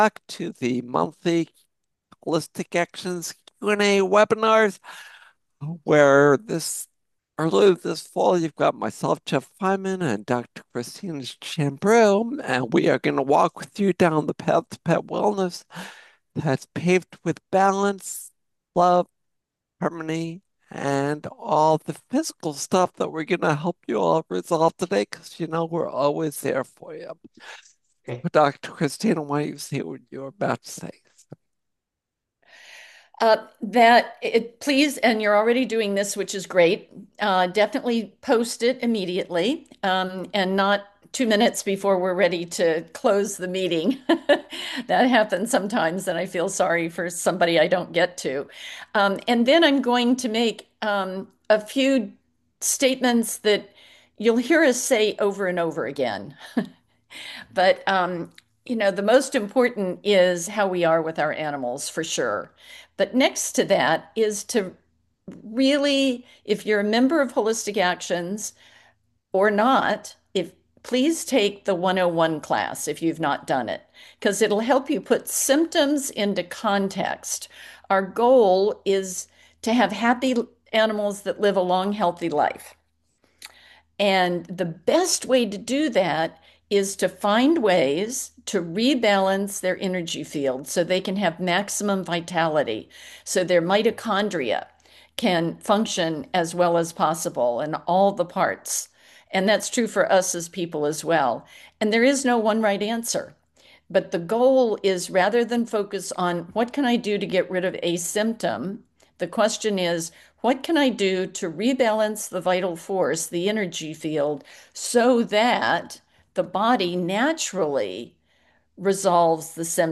Ask A Vet – Live Q&A 9/26/25 - Holistic Actions!
Summary of Weekly Q&A Session Topics: